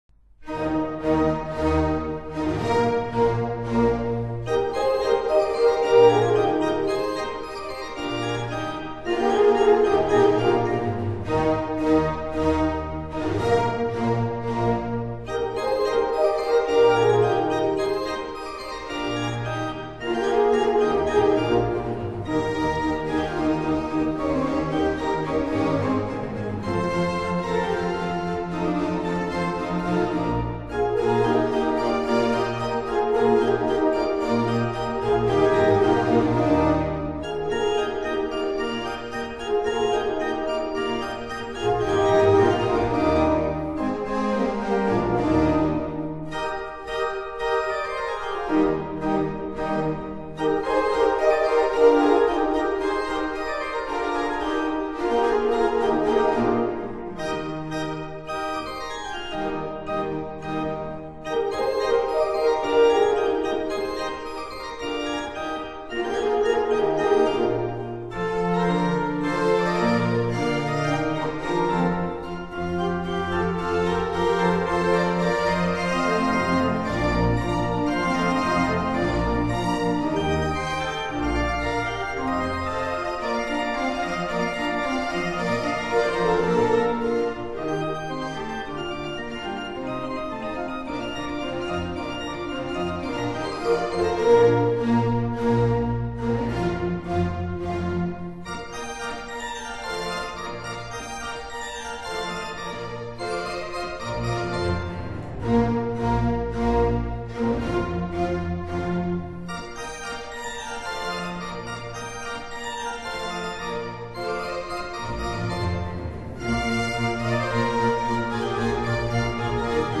Concerto for organ,2 horns & strings in E flat major Op. I/1